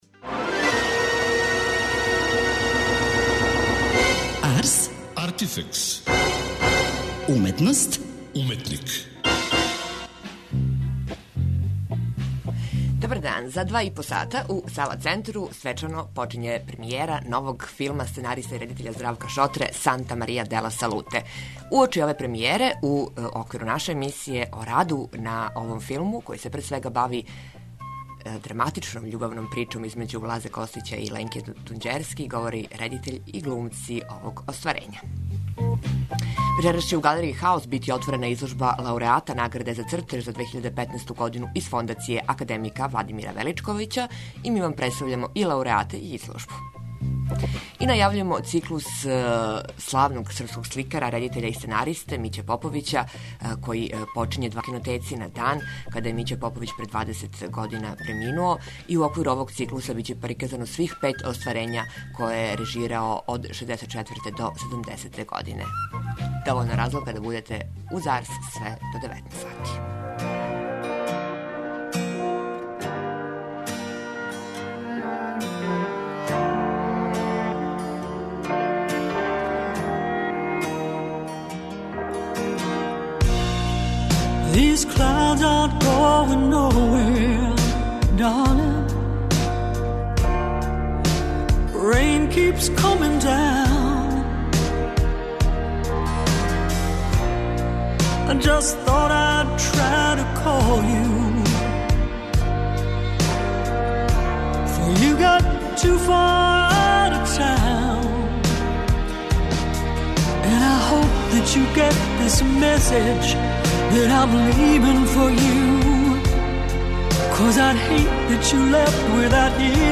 О раду на овом пројекту у емисији говоре Здравко Шотра и глумци.